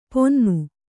♪ ponnu